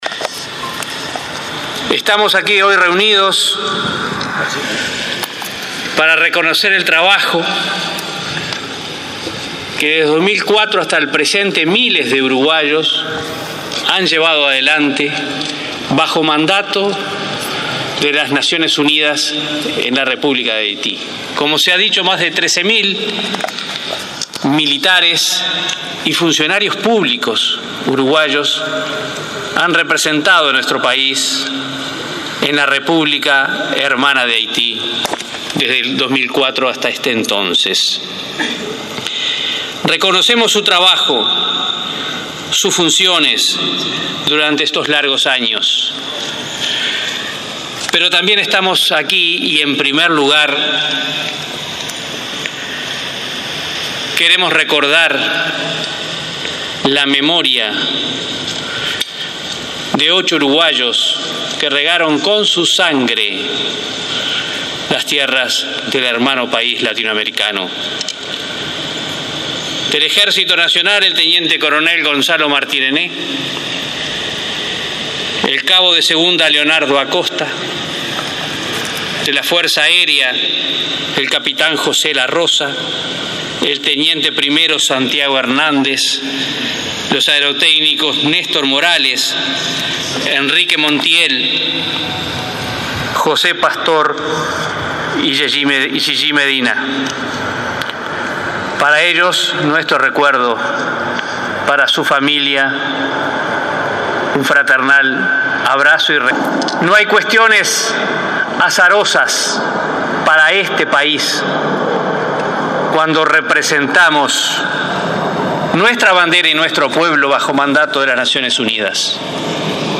Más de 13 mil militares han representado a nuestro país desde el 2004 hasta ahora en misiones de paz en Haití, destacó el ministro de Defensa, Jorge Menéndez, durante el acto homenaje a dichos efectivos, al que también asistió el canciller Nin Novoa. Menéndez sostuvo que cuando Uruguay actúa en el exterior va a defender la paz y el desarrollo de zonas lejanas.